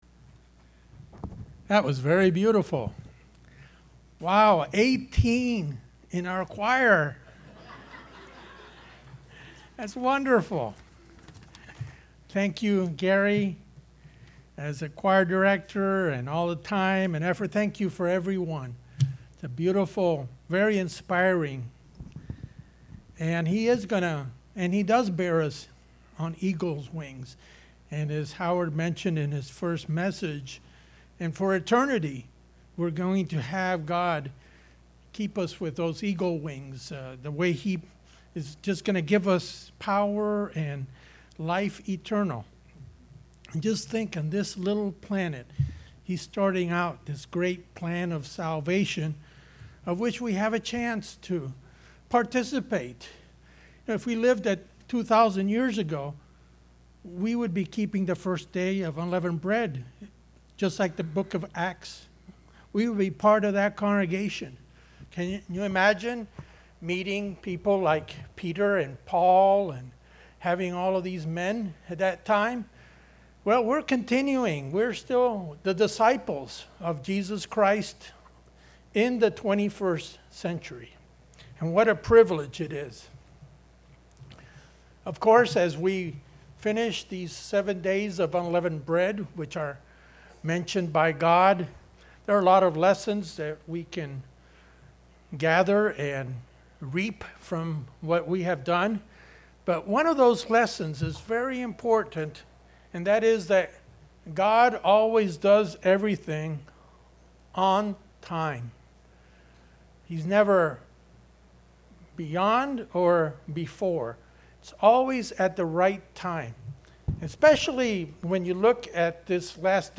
What are the symbols God gave us to remember the Days of Unleavened Bread? In this message, we will look at the chronology of events that took place when the Israelites left Egypt in relation to these Days of Unleavened Bread.